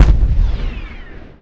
fire_turret_dreadnought.wav